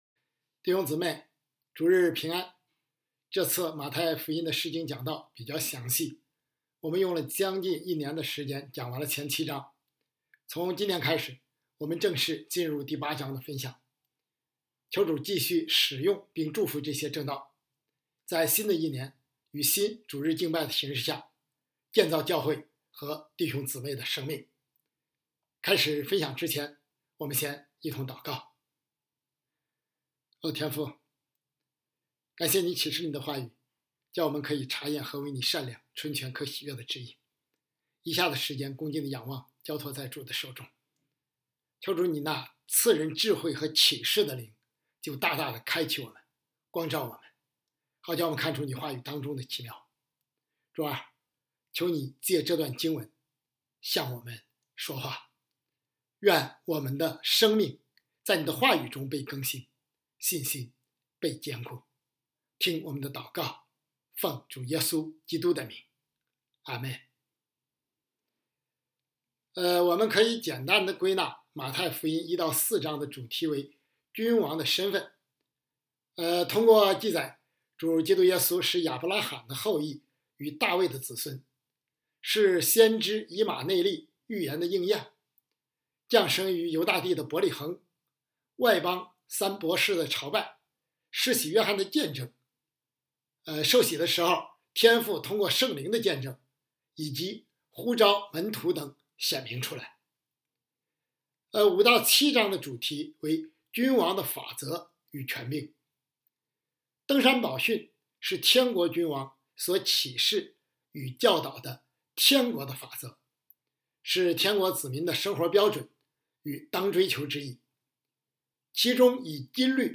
你洁净了吧——2025年1月19日主日讲章